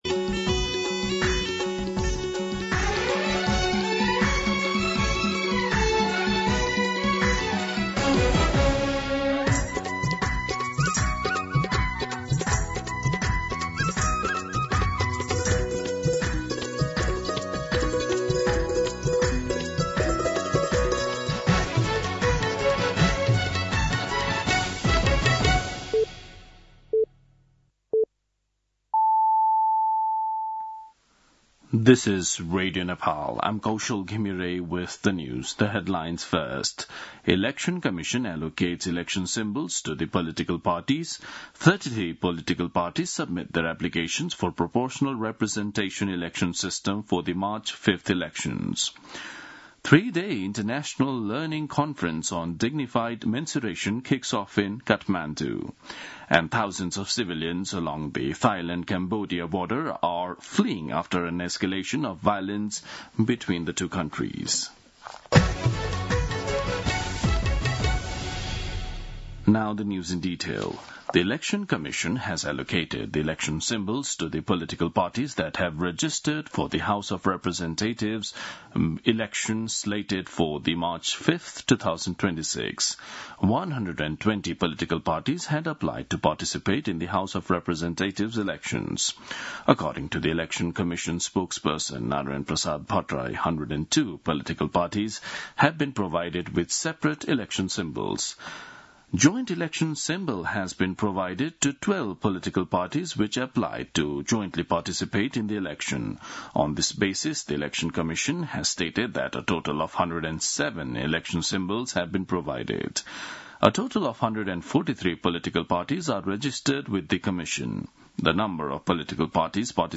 दिउँसो २ बजेको अङ्ग्रेजी समाचार : २२ मंसिर , २०८२